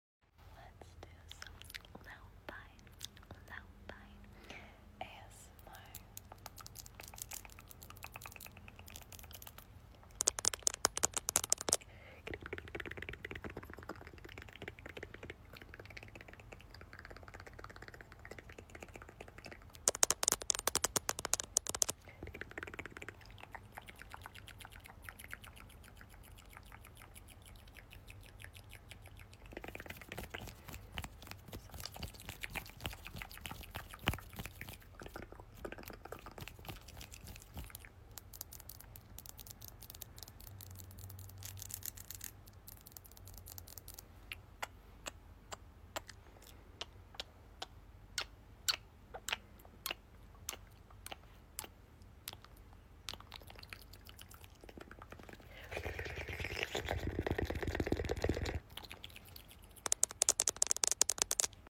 Tingly Mouthsounds Asmr